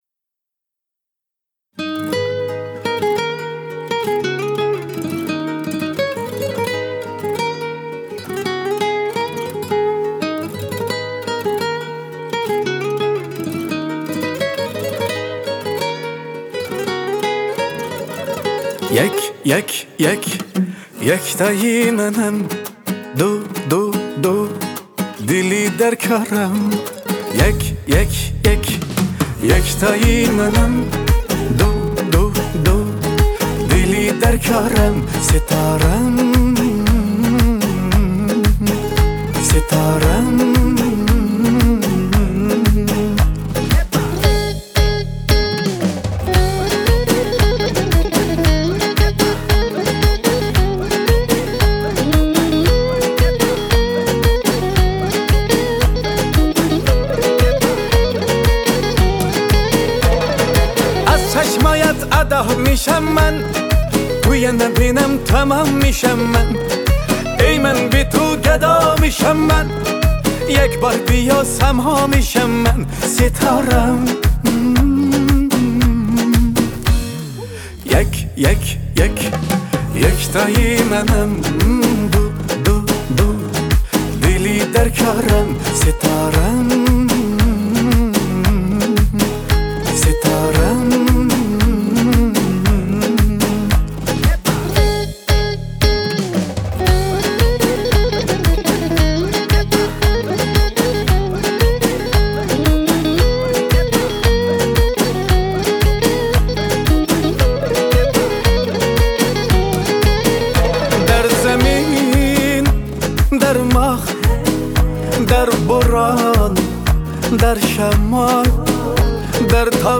Музыка / 2024- Год / Таджикские / Поп / Прочее